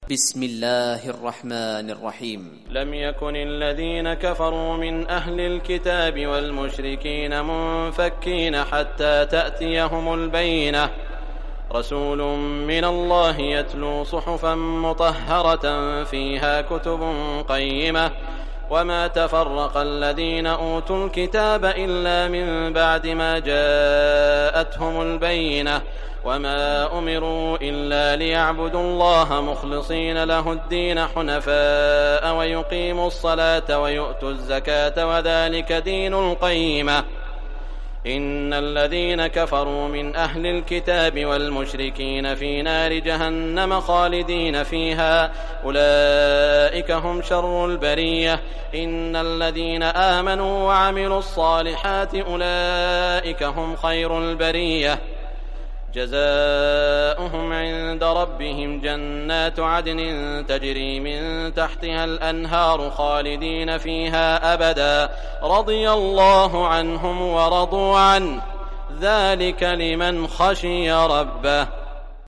Surah Al-Bayyinah Recitation by Sheikh Shuraim
Surah Al-Bayyinah, listen or play online mp3 tilawat / recitation in Arabic in the beautiful voice of Sheikh Saud al Shuraim.